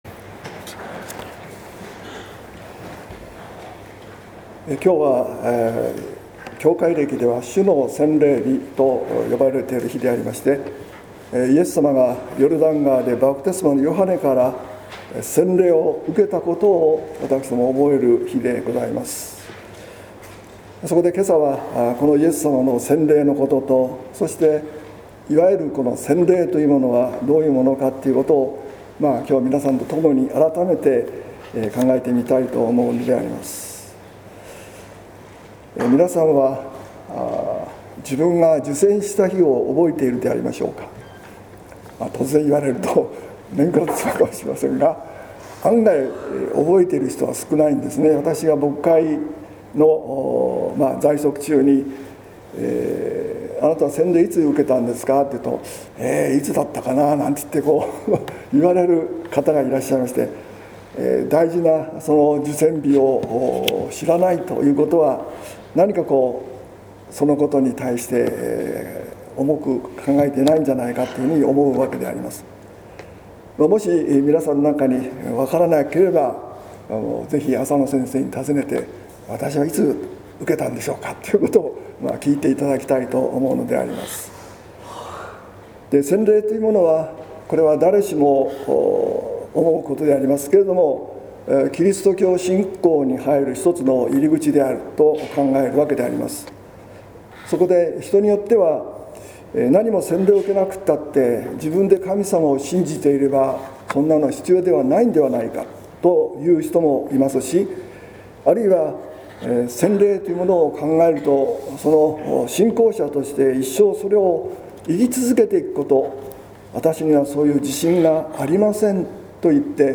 説教「恵みの賜物としての洗礼」（音声版）